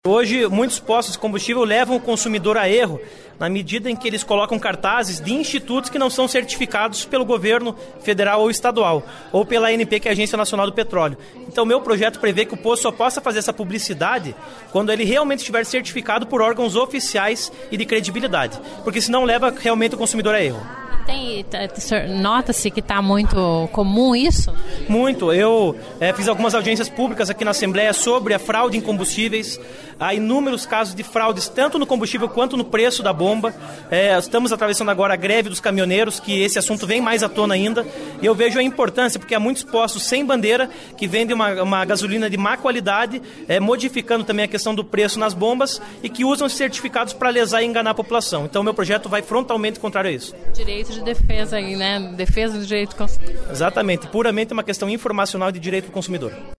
Ouça entrevista do  deputado Felipe Francischini (PSL), onde detalha  o projeto de lei de autoria dele e que passou em primeira discussão na sessão plenária desta segunda-feira (28), que proíbe os postos e as empresas revendedoras de combustíveis do Paraná  de veicularem informações sobre a qualidade dos combustíveis sem uma comprovação, que seria um  atestado fornecido pelos órgãos oficiais.